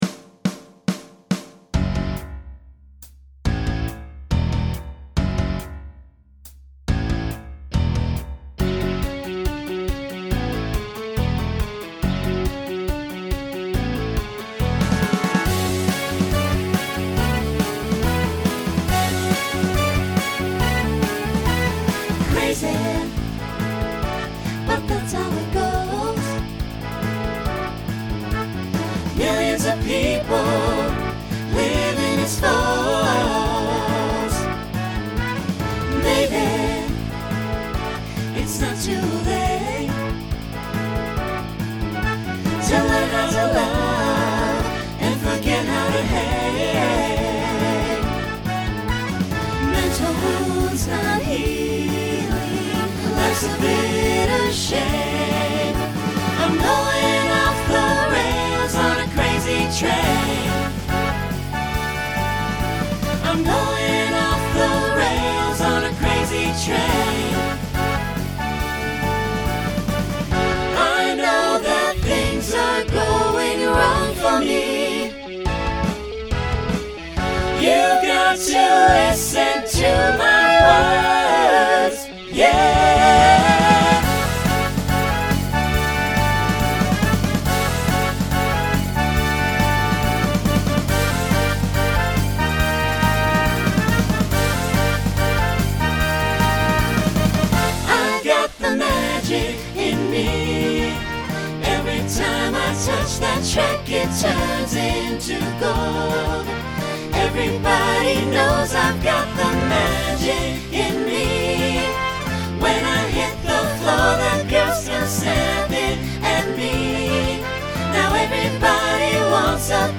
2010s Genre Pop/Dance , Rock
Opener Voicing SATB